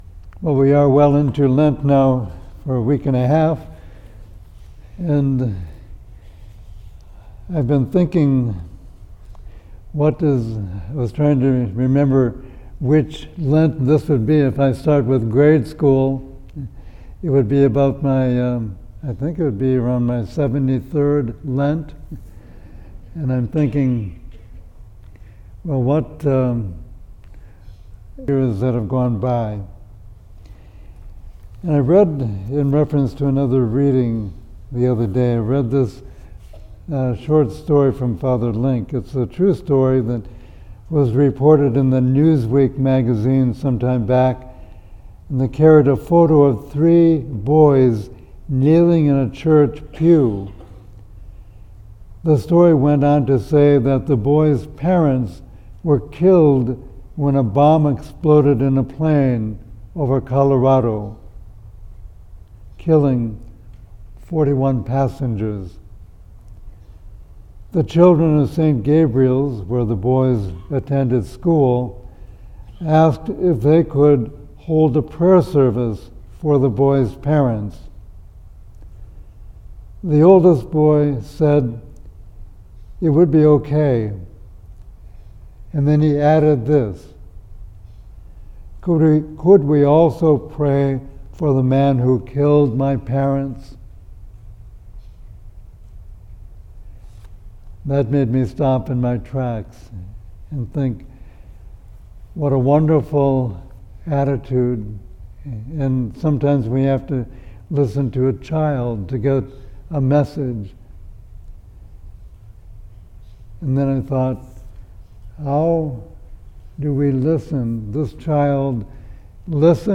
Lent Sermon
Homily-2nd-sol23.mp3